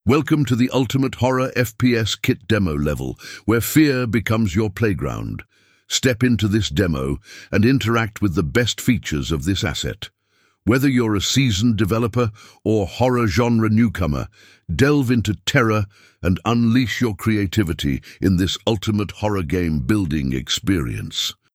(Narration) Welcome.wav